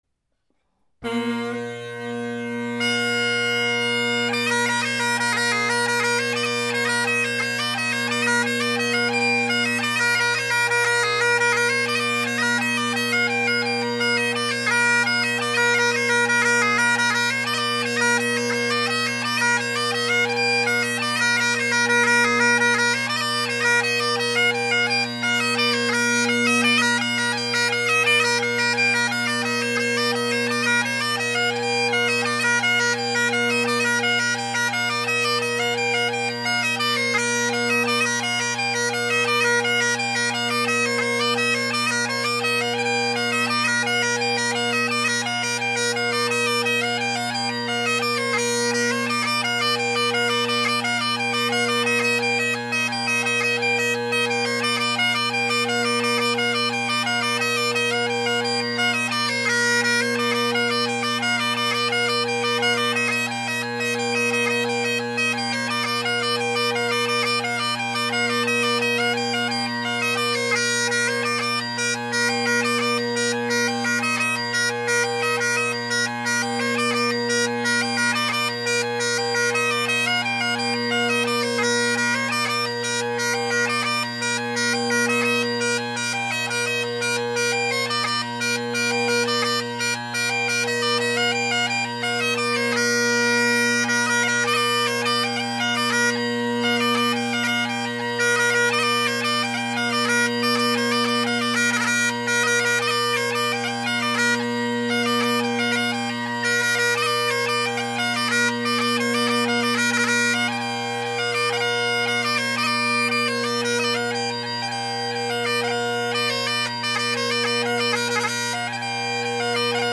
Great Highland Bagpipe Solo